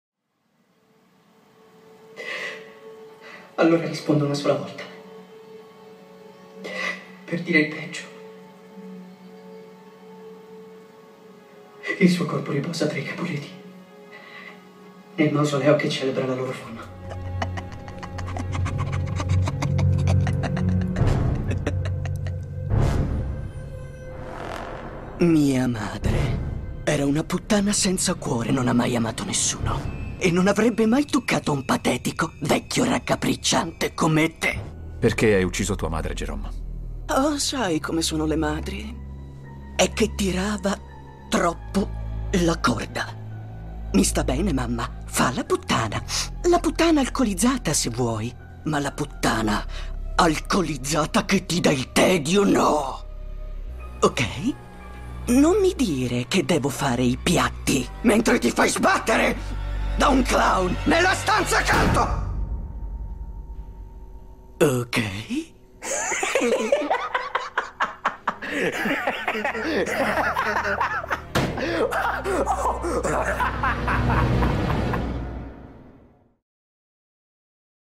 nel film "Romeo & Juliet", in cui doppia Kodi Smit-McPhee, e nel telefilm "Gotham", in cui doppia Cameron Monaghan.